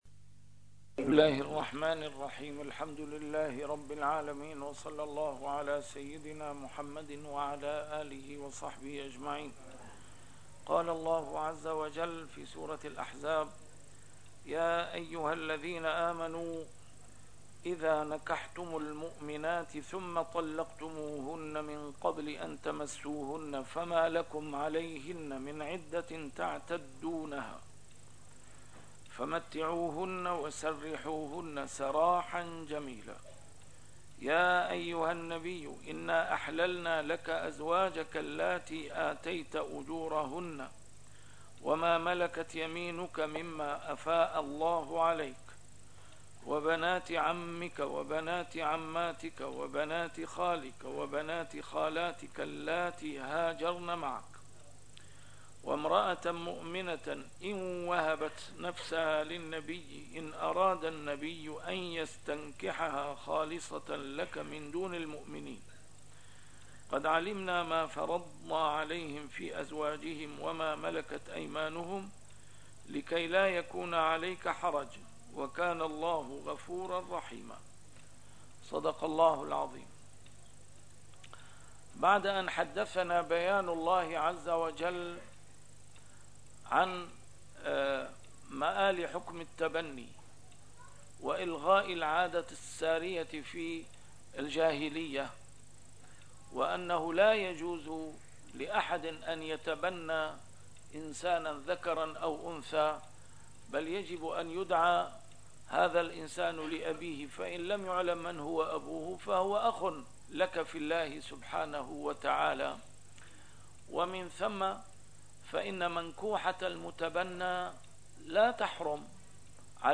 A MARTYR SCHOLAR: IMAM MUHAMMAD SAEED RAMADAN AL-BOUTI - الدروس العلمية - تفسير القرآن الكريم - تسجيل قديم - الدرس 378: الأحزاب 49-50